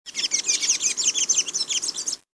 Thraupis episcopus (blue-grey tanager)
Blue-gray Tanagers (Thraupis episcopus) are common in Costa Rica. Here is some chatter.